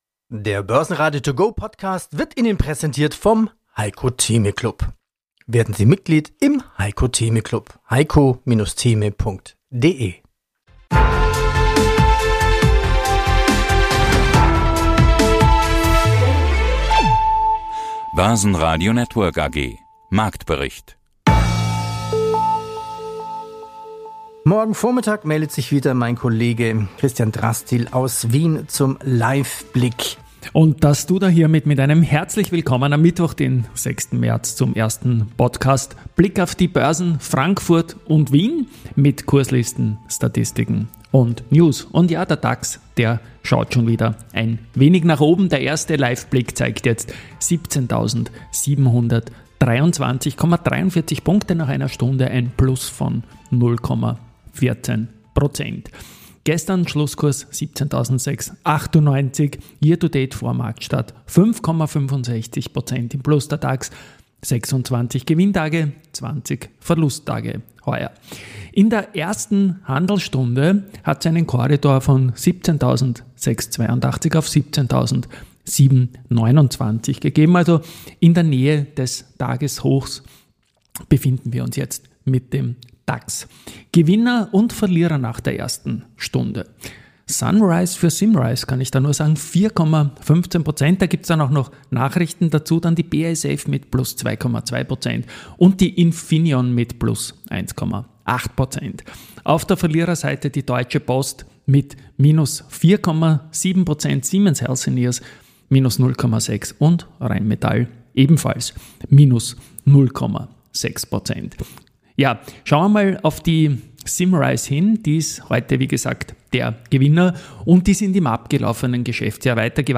Die Börse zum hören: mit Vorstandsinterviews, Expertenmeinungen und Marktberichten.